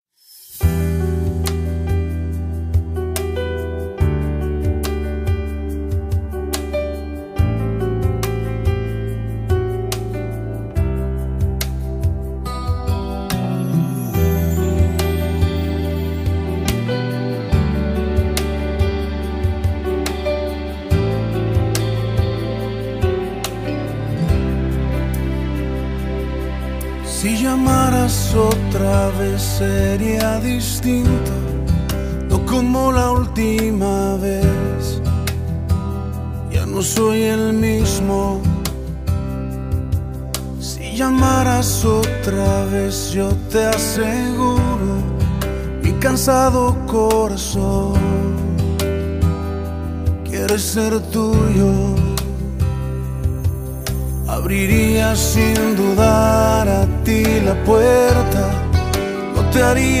Preciosa Alabanza para el Señor 🙏🏻
maravilloso concierto!